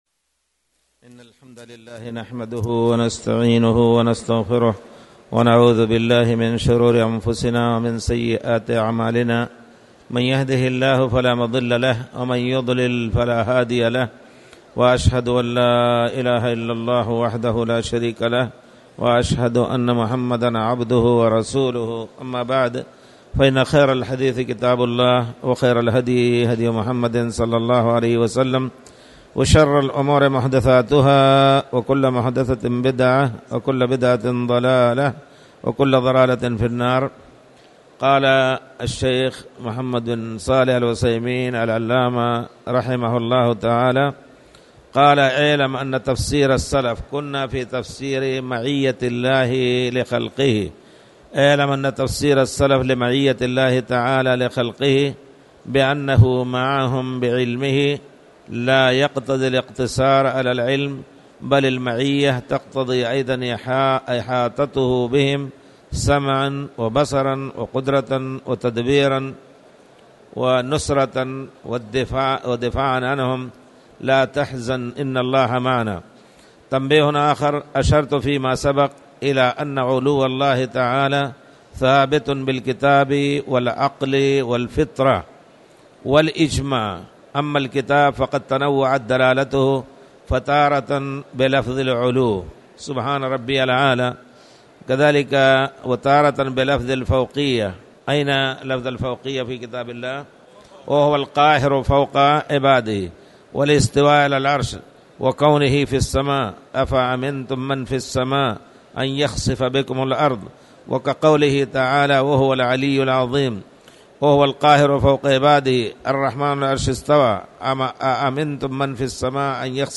تاريخ النشر ٢٩ رمضان ١٤٣٨ هـ المكان: المسجد الحرام الشيخ